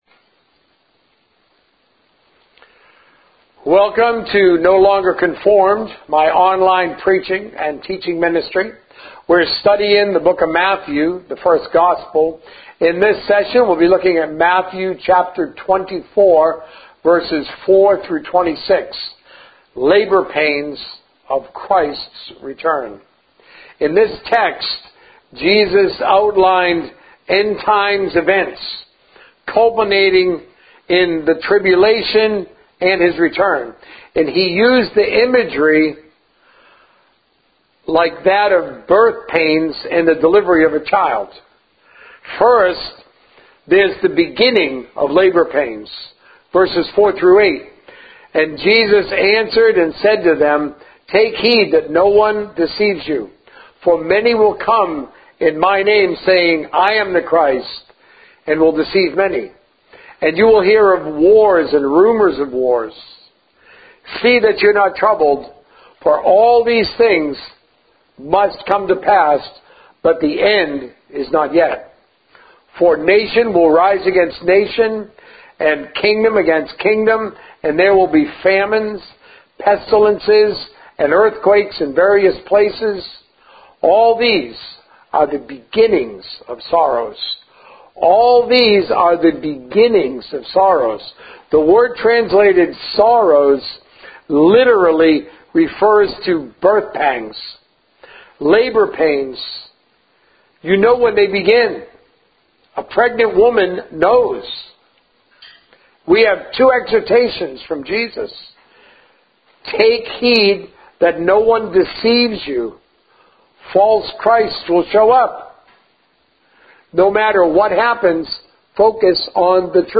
A message from the series "The Source of Success."